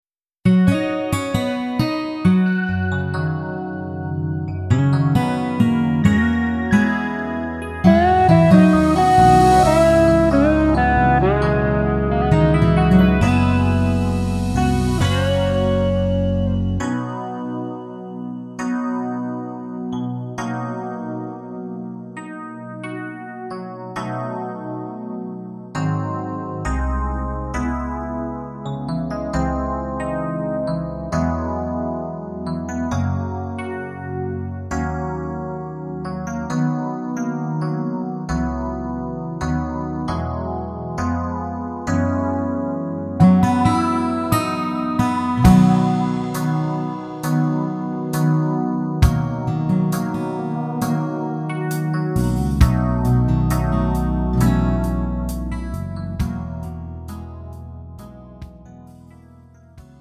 음정 -1키 3:37
장르 가요 구분 Pro MR